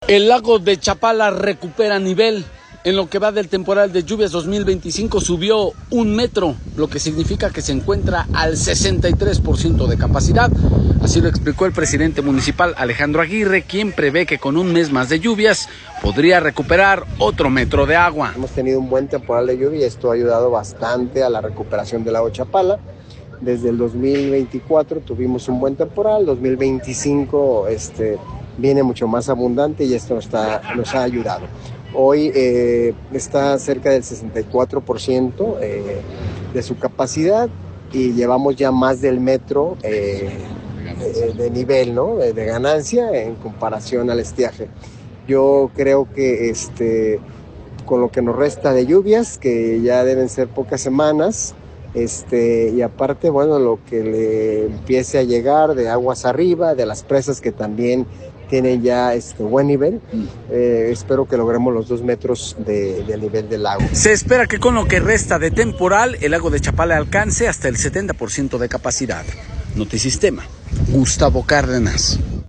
El lago de Chapala recupera nivel, pues en lo que va del temporal de lluvias 2025 subió 1 metro, lo que significa que se encuentra al 63 por ciento de su capacidad, así lo explicó el presidente municipal Alejandro Aguirre, quien prevé que con un mes más de lluvias podría recuperar otro metro de agua.